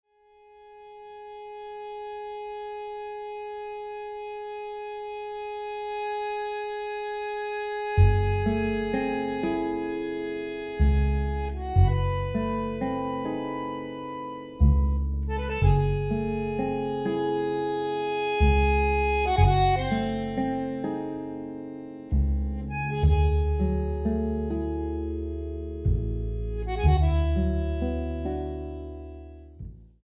bandoneón